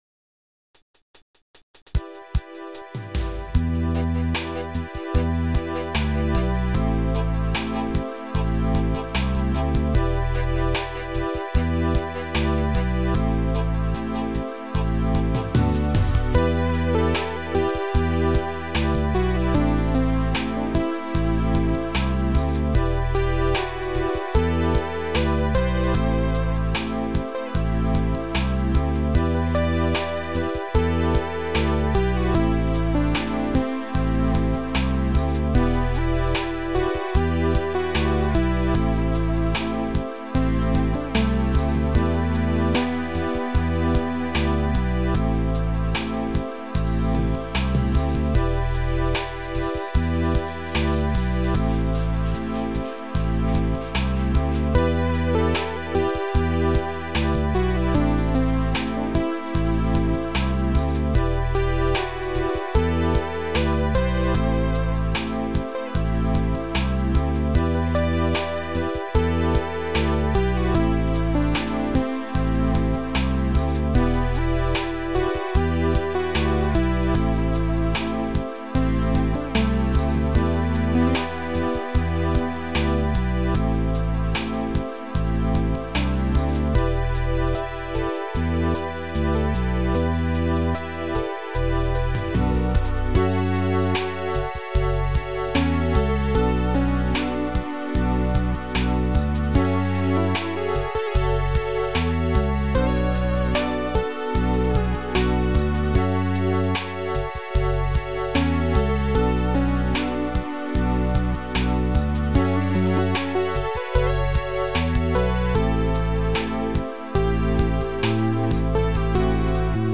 Sound Style: Mellow